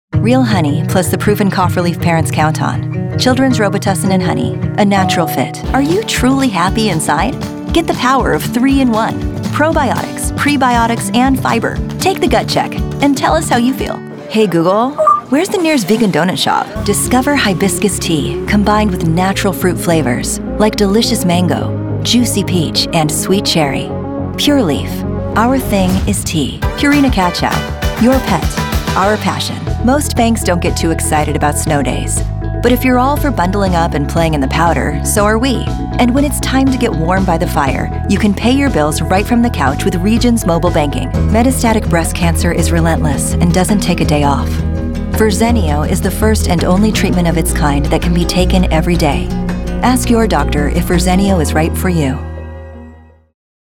Demo
Teenager, Adult, Young Adult
Has Own Studio
standard us
commercial
friendly
story telling
trustworthy
warm